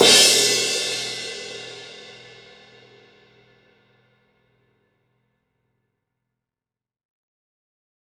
BR Crash.WAV